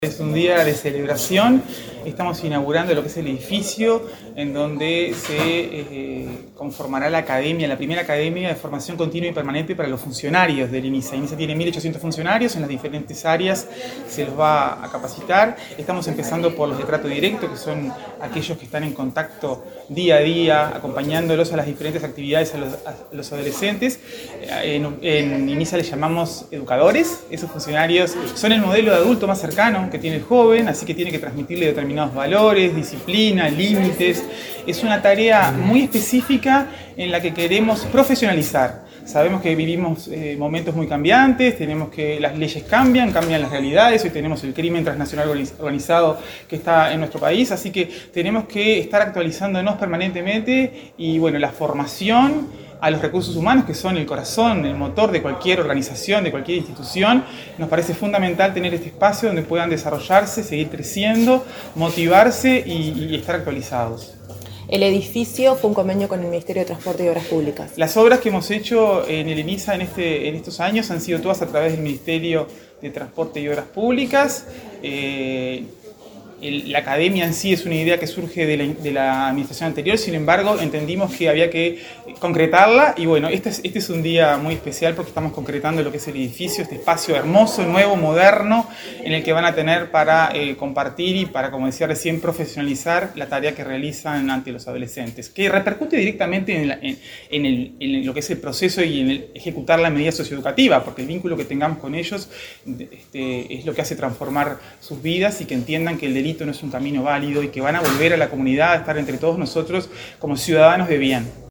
Entrevista a la presidenta de Inisa, Rosanna de Olivera